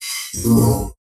drone2.ogg